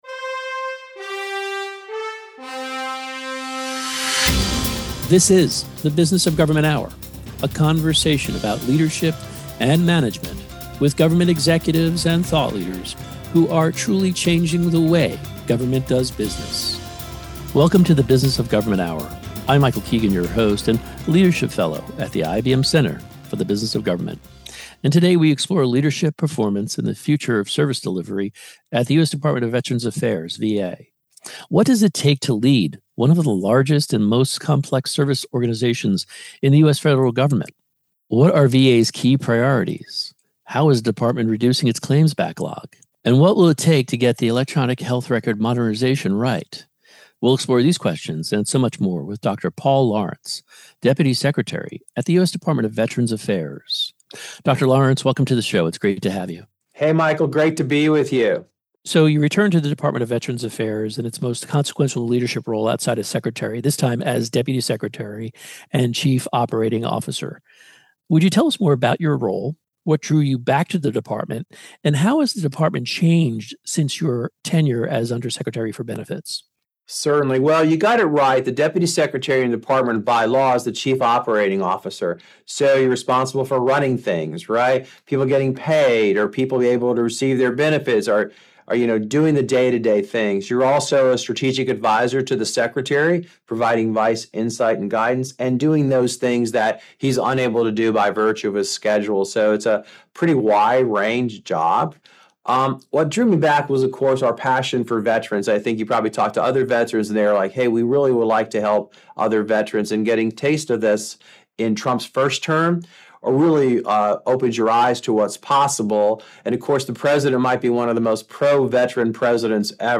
Radio Hour Share Podcast TV Hour The Business of Government Hour Stay connected with the IBM Center Download or Email Listen to the Business of Government Hour Anytime, Anywhere Video not available